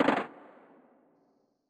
Claps / Drowned Clap